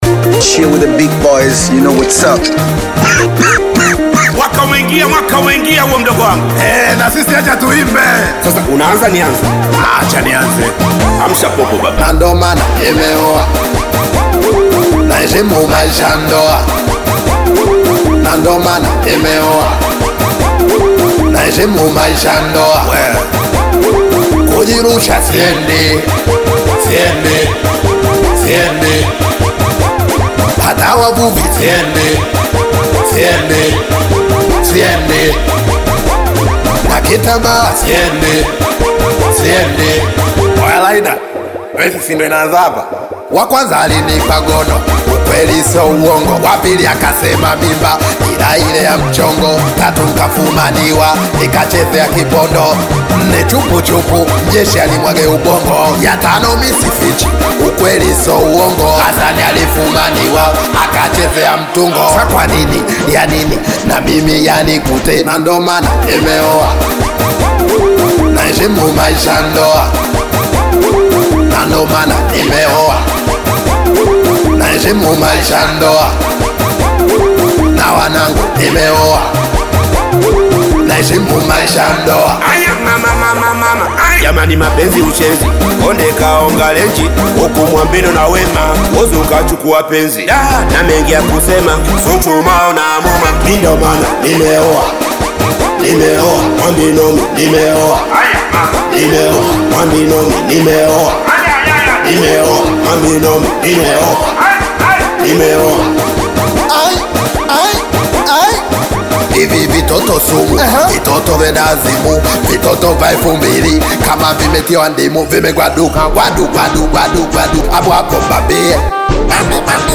Singeli music track
Singeli song